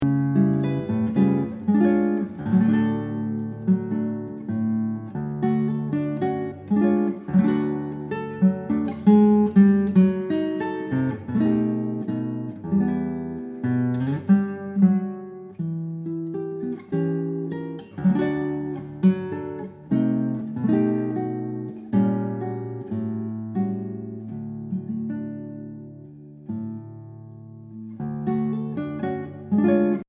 Obras para guitarra